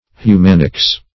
Search Result for " humanics" : The Collaborative International Dictionary of English v.0.48: Humanics \Hu*man"ics\ (h[-u]*m[a^]n"[i^]ks), n. The study of human nature.